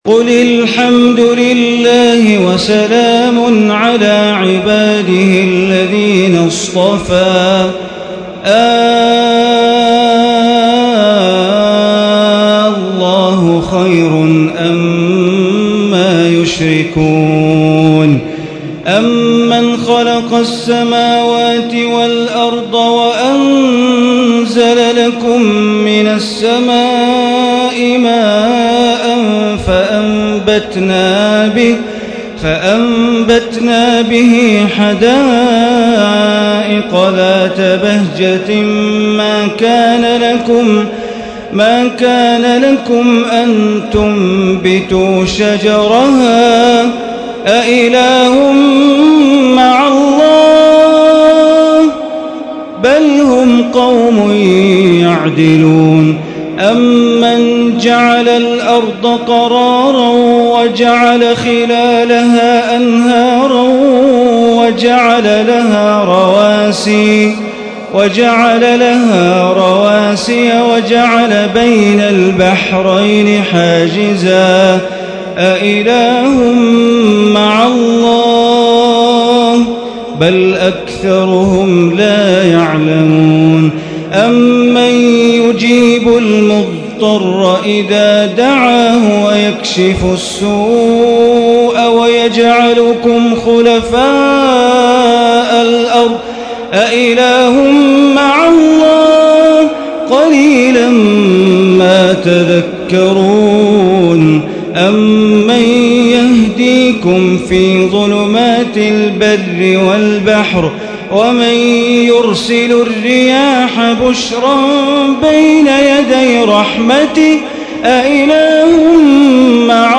ليلة 19 من رمضان عام 1436 من سورة النمل آية 59 إلى سورة القصص آية 50 > تراويح ١٤٣٦ هـ > التراويح - تلاوات بندر بليلة